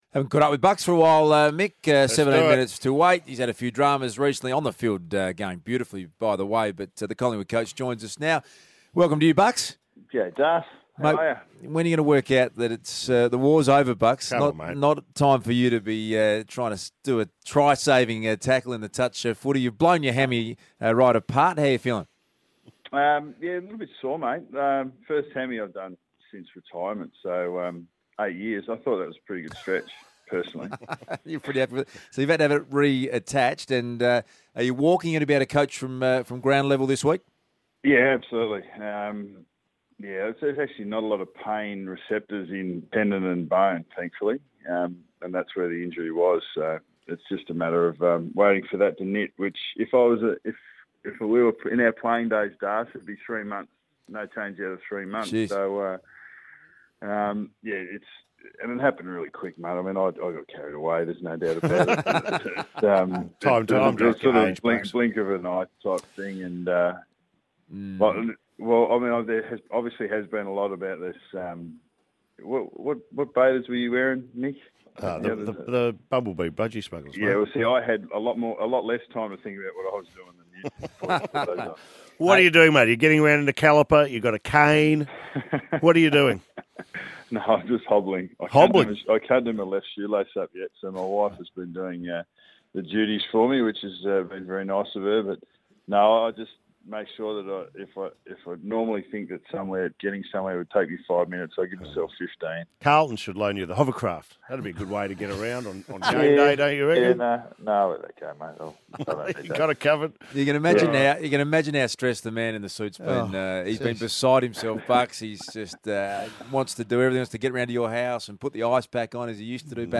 Radio: Nathan Buckley on Triple M
Listen to coach Nathan Buckley join Eddie McGuire, Mick Molloy and Luke Darcy on Triple M's Hot Breakfast on Friday 12 June.